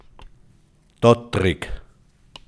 dåddrig / Begriff-ABC / Mundart / Tiroler AT / Home - Tiroler Versicherung
Reith im Alpbachtal